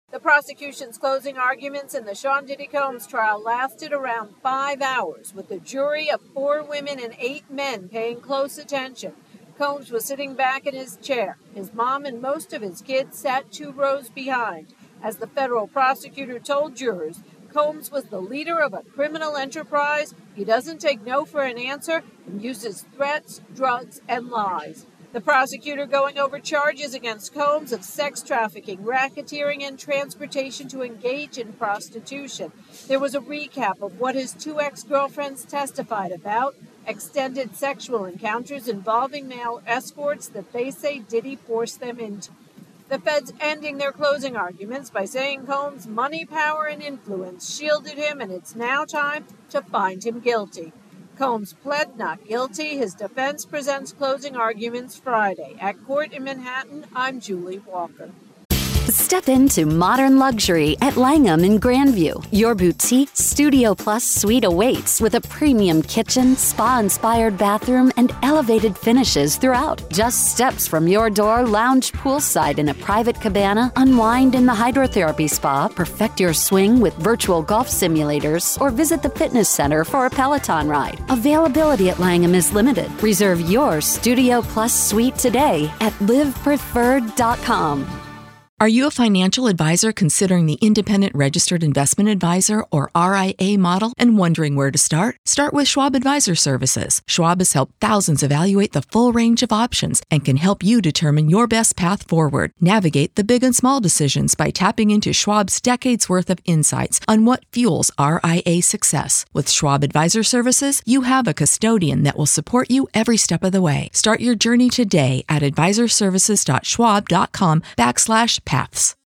reports from court